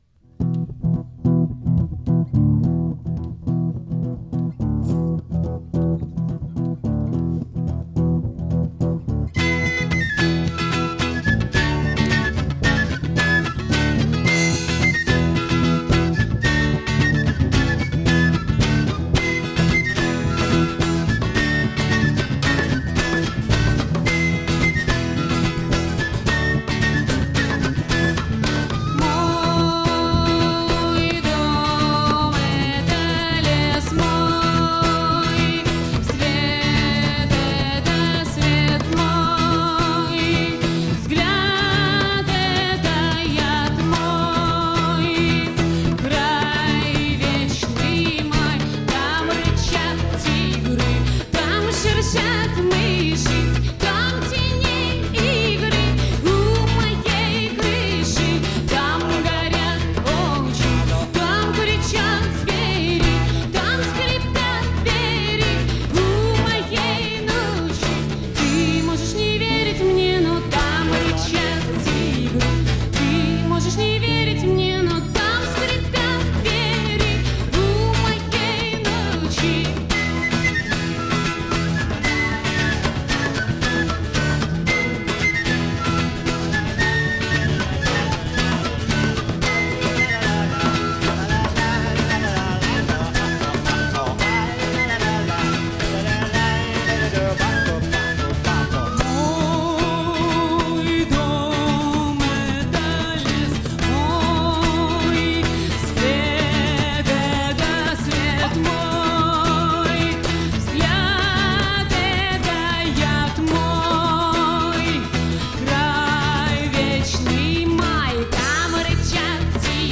Концертный диск, стиль — акустика.
голос, гитара
бас, мандолина, голос
перкуссия
саксофон, флейта